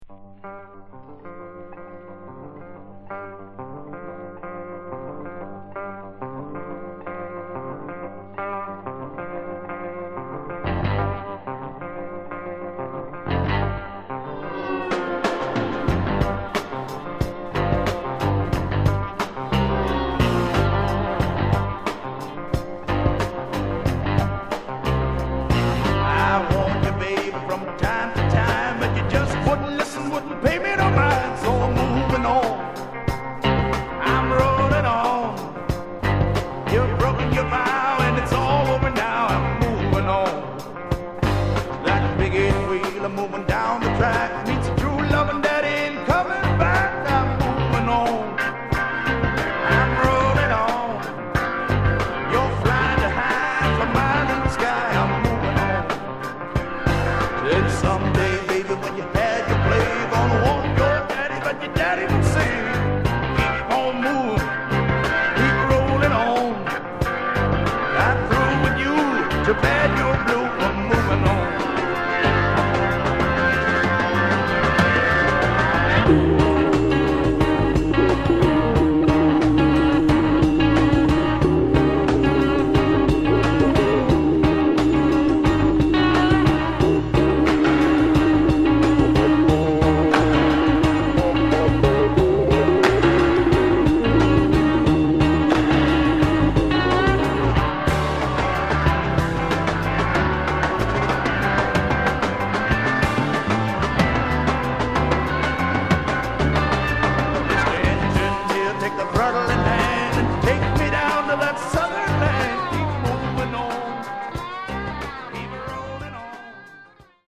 Genre: Hard Rock/Metal